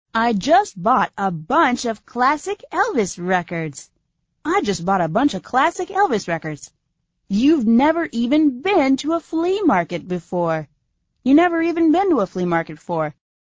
《发音练习》